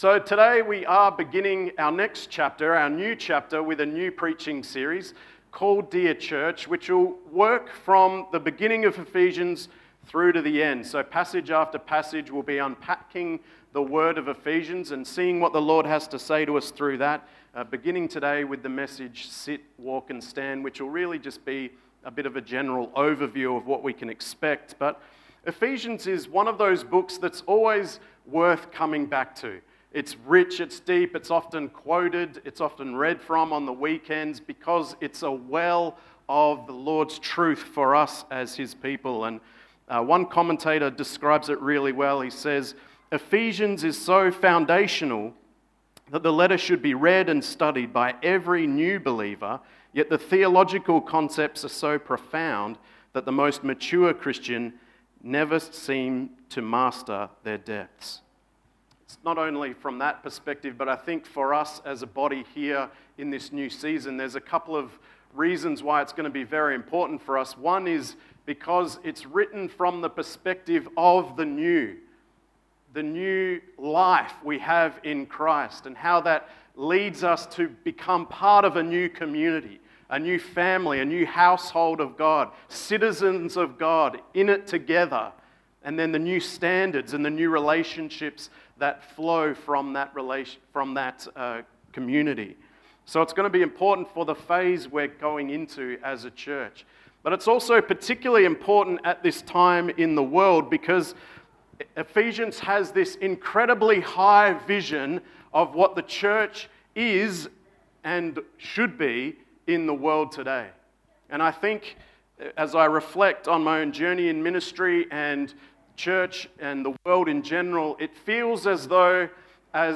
Sermons | Coolbellup Community Church